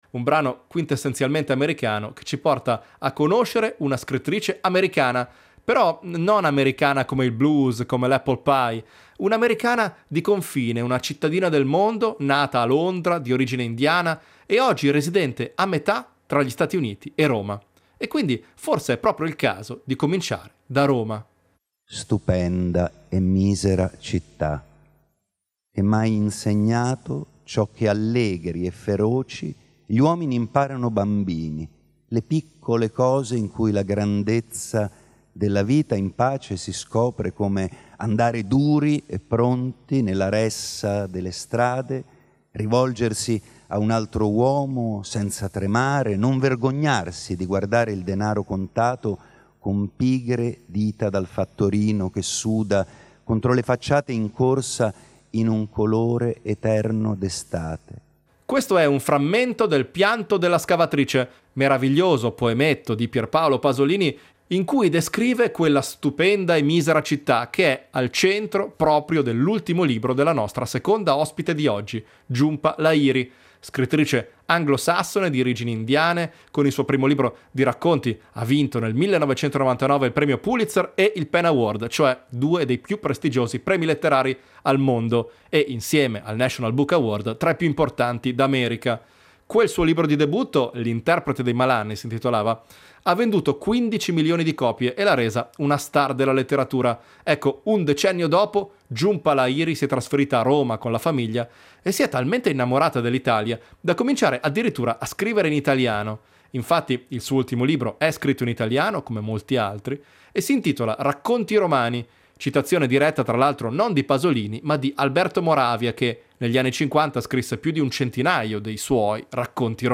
con Jhumpa Lahiri al microfono di Michele Serra